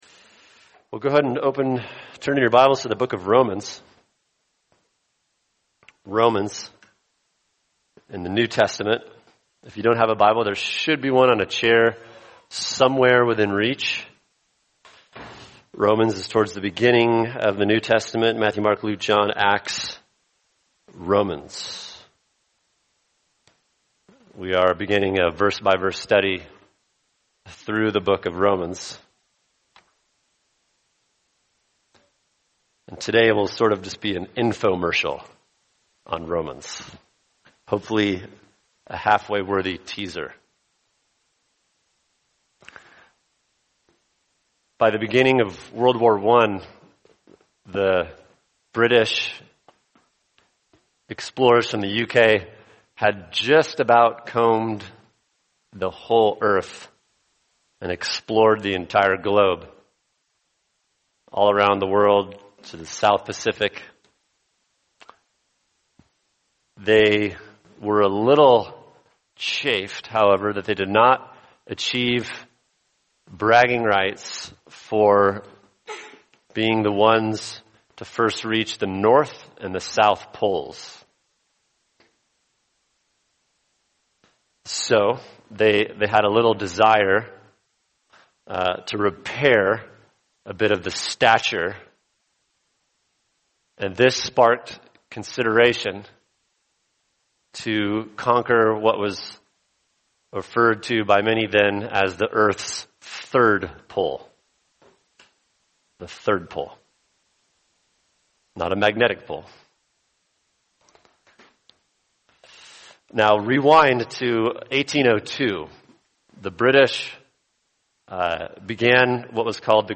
[sermon] An Introduction to Romans | Cornerstone Church - Jackson Hole